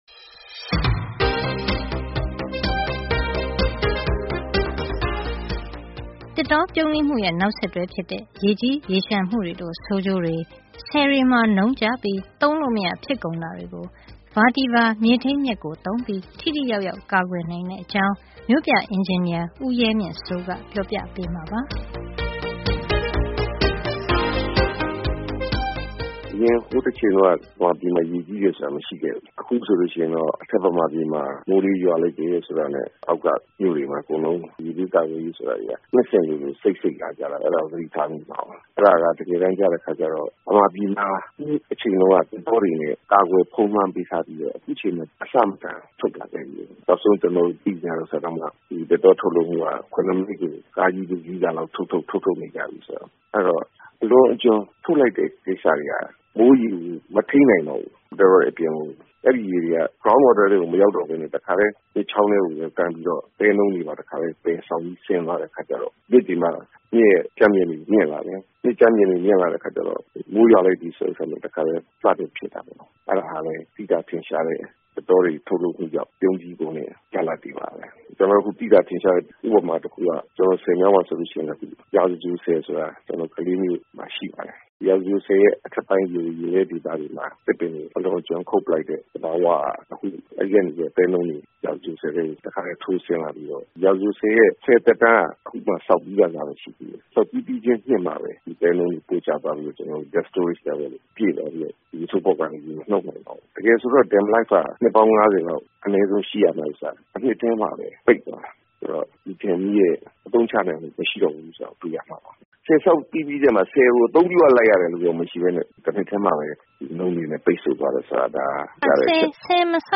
မြို့ပြအင်ဂျင်နီယာ